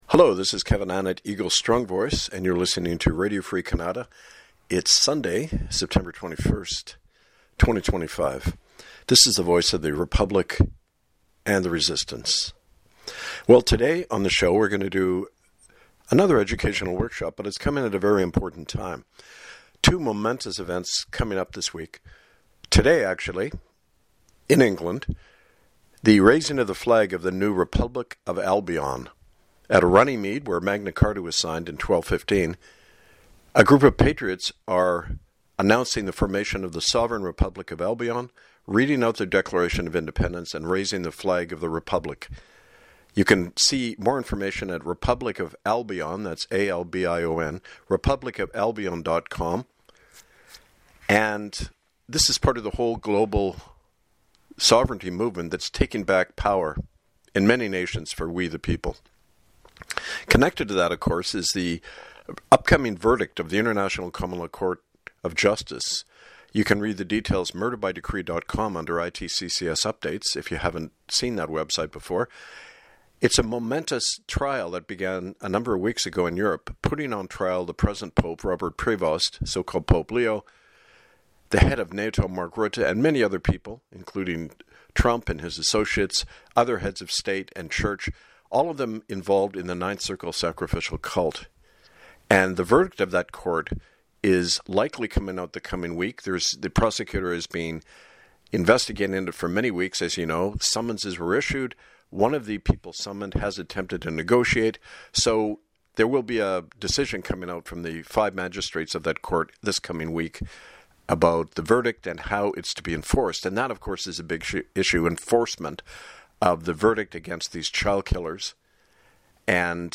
Talk Show Episode, Audio Podcast, Radio Free Kanata and What is Genocide and how does it continue?